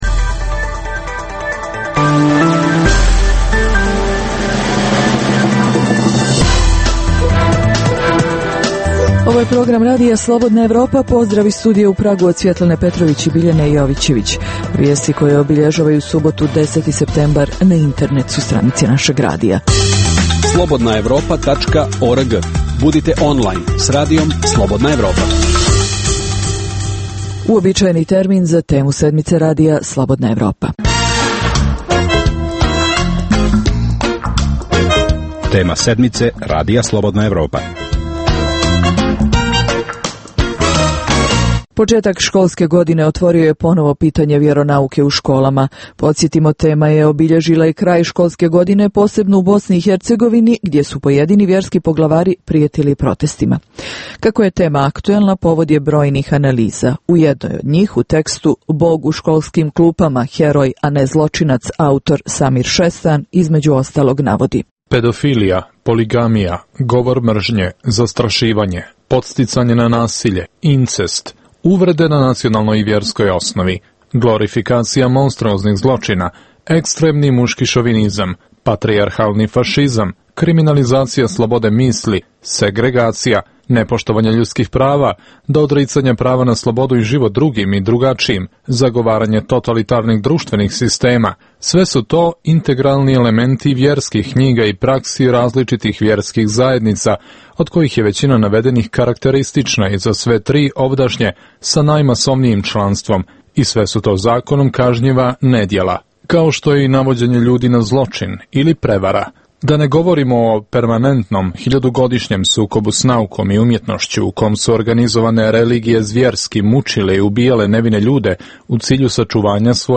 Preostalih pola sata emisije, nazvanih "Tema sedmice" sadrži analitičke teme, intervjue i priče iz života, te rubriku "Dnevnik", koji za Radio Slobodna Evropa vode poznate osobe iz regiona.